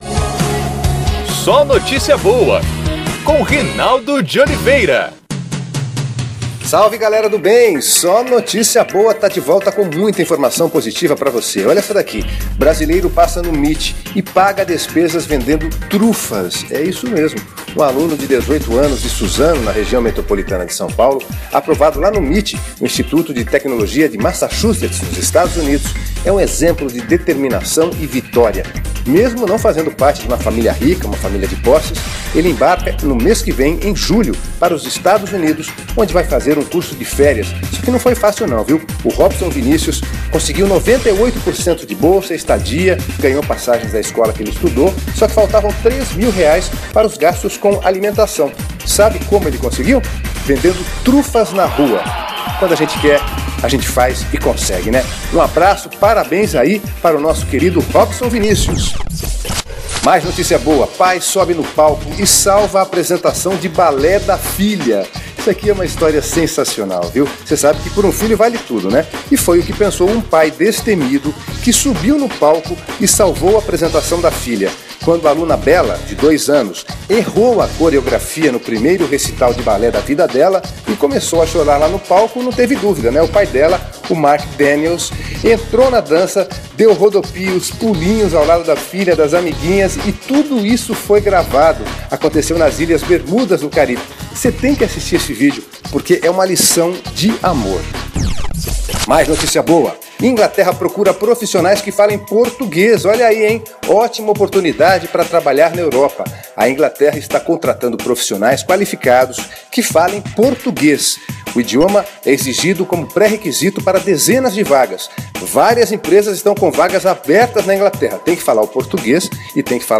É podcast SóNotíciaBoa, nosso programa de rádio.